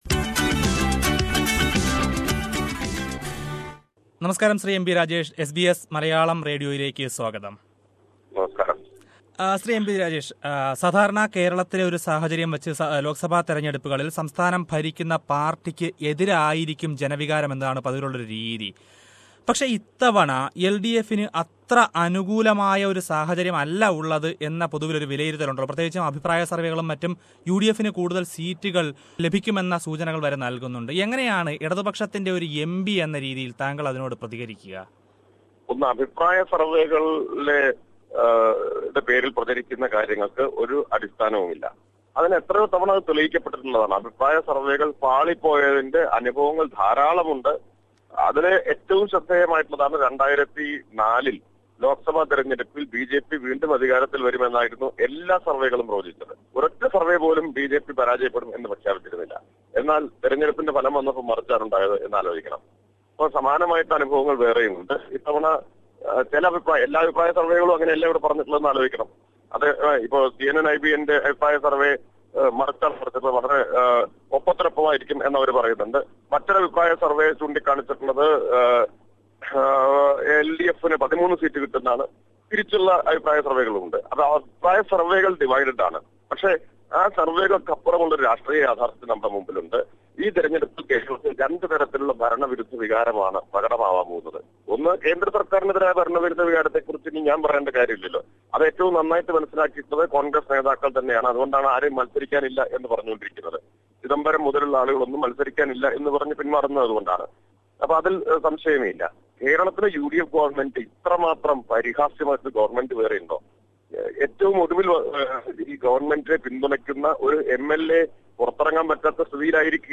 We are bringing to you interviews with leaders of all major political parties in India, and we ask them the questions in your mind. Let us listen to an interview with M.B.Rajesh, Member of Parliament from Palakakd and CPI(M) leader.